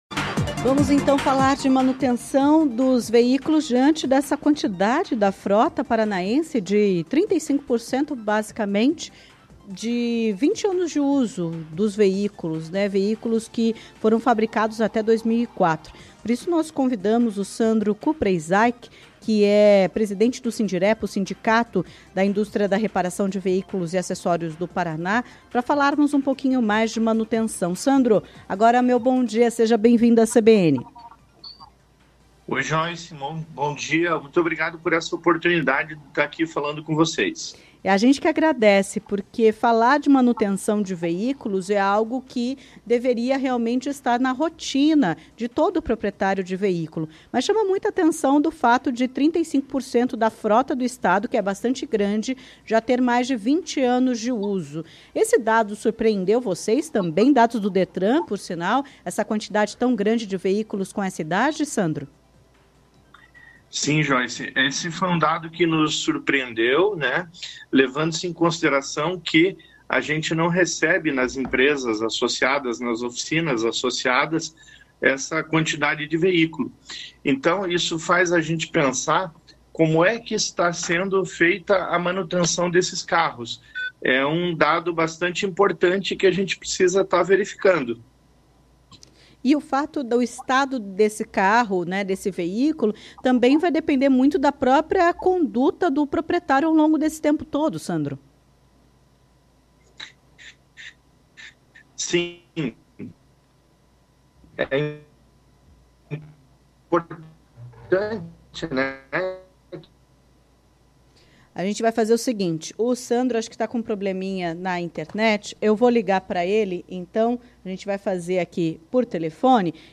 em entrevista à CBN Curitiba.